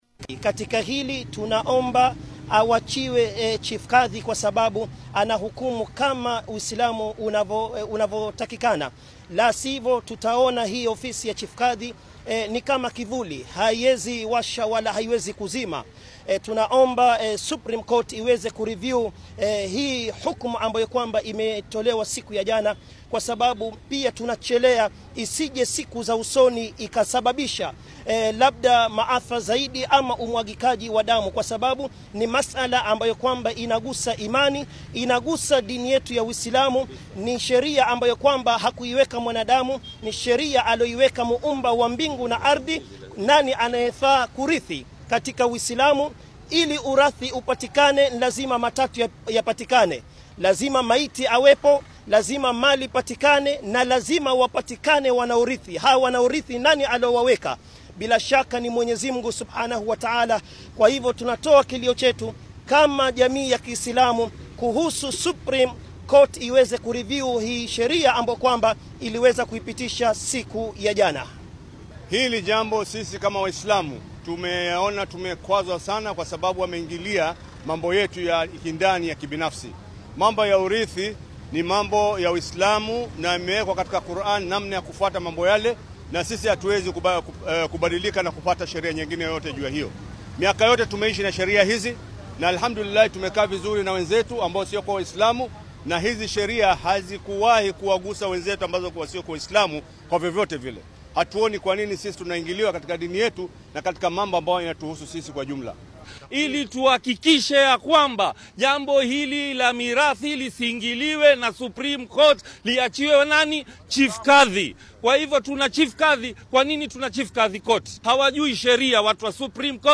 Qaar ka mid ah Culimaa’udiinka Muslimiinta ee gobolka Xeebta ayaa si weyn uga soo horjeestay go’aan dhawaan kasoo baxay Maxkamadda ugu Sareysa dalka ee Supreme Court oo ahaa in carruurta aan lagu dhalin guurka ay dhaxli karaan hantida waalidiintooda iyagoo ku tilmaamay inay tahay mid xadgudub ku ah shareecada Islaamka iyo awoodda Maxkamadaha Kadhiga. Iyagoo ka hadlayay shir jaraa’id oo ay ku qabteen magaalada Mombasa, culumada ayaa sheegay in xukunkan uu wax u dhimayo xorriyadda diimaha ee dastuurku aqoonsan yahay, isla markaana ka hor imaanayo manhajka Islaamka ee la xiriira dhaxalka.